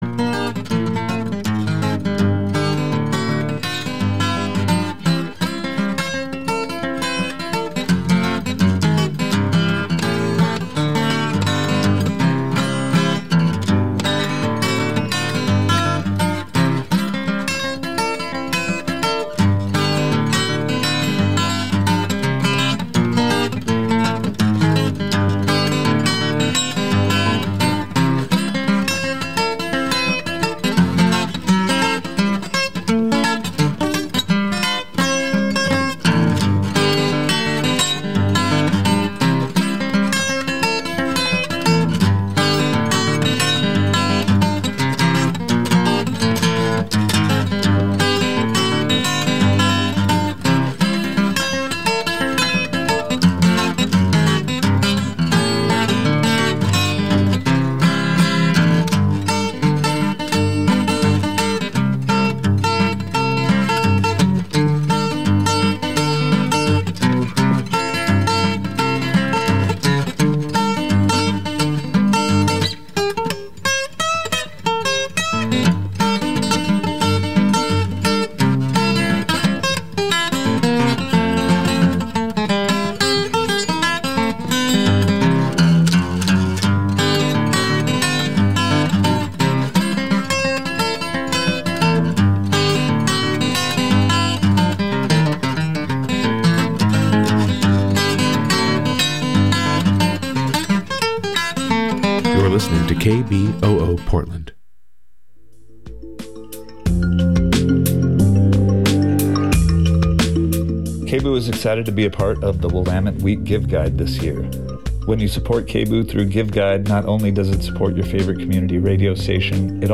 Every 1st Monday from 7:00 pm to 8:00 pm Let’s Talk About Race (LTAR), a new intergenerational, roundtable discussion of independent national journalists featuring rigorous conversations and analysis of news coverage and the role race plays in politics, government, economy, education, and health. LTAR currently airs on KBOO Community Radio in Portland, OR .